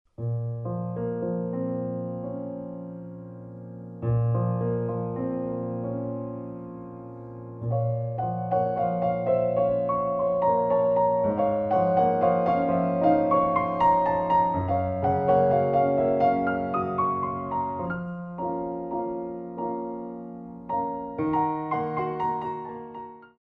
Révérence